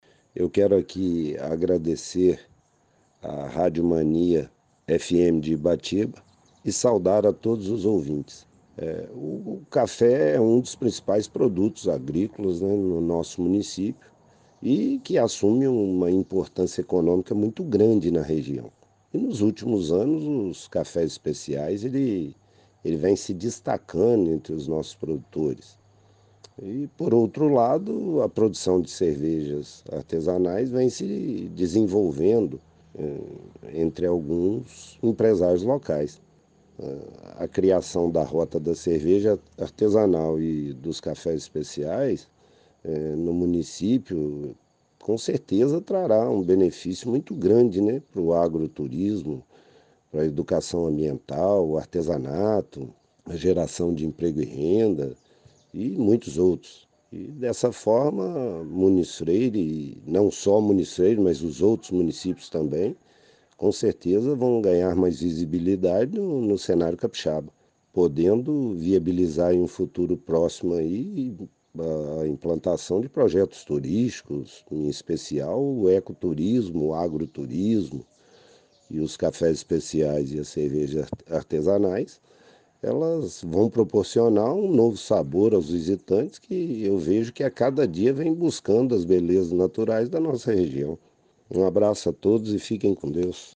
O prefeito Dito Silva, de Muniz Freire, também conversou com a Mania FM e discorreu sobre os impactos positivos para Muniz Freire e região, e também sobre a  possibilidade de ampliar o projeto a médio e longo prazo.